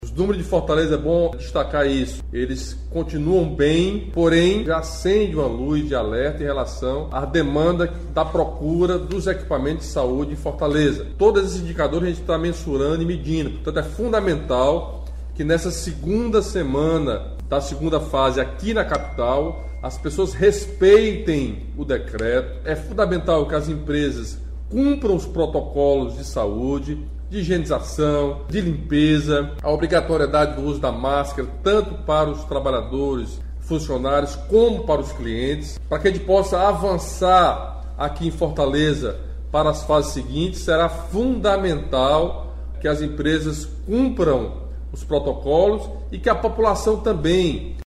O governador Camilo Santana informou a decisão durante pronunciamento oficial nas redes sociais, na noite deste sábado (27).